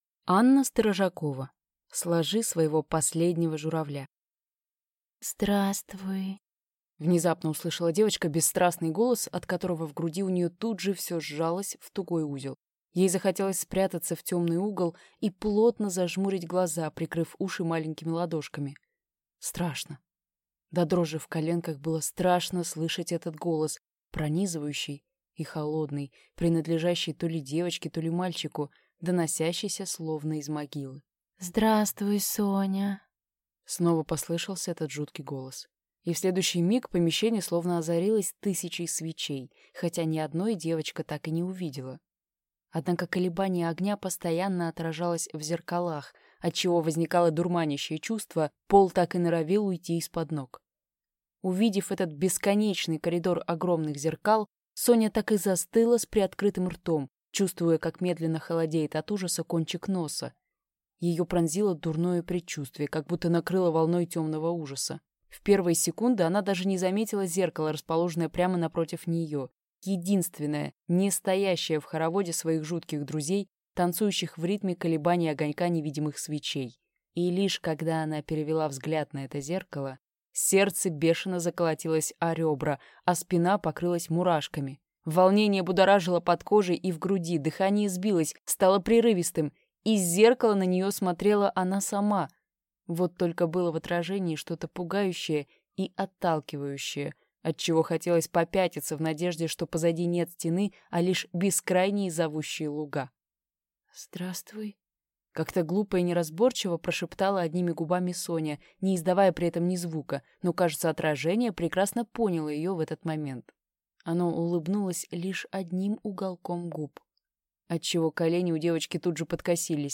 Аудиокнига Сложи своего последнего журавля | Библиотека аудиокниг
Прослушать и бесплатно скачать фрагмент аудиокниги